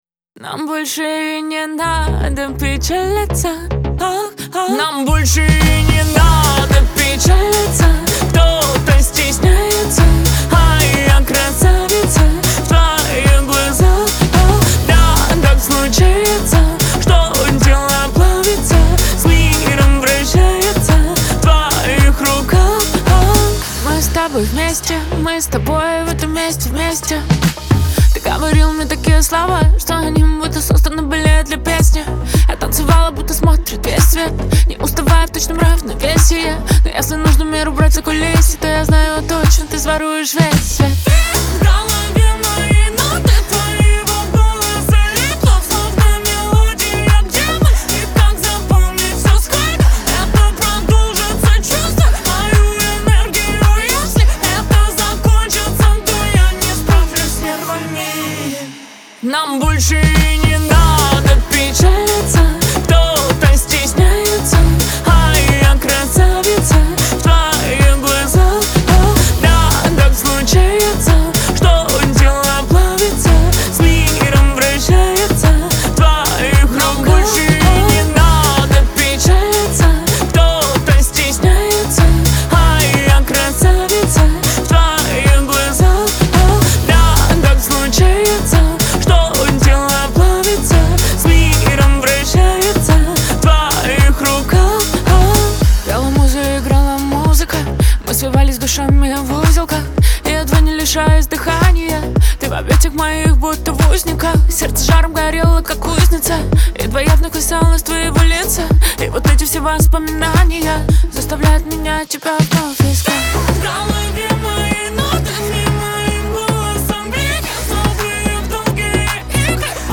диско , эстрада
танцевальная музыка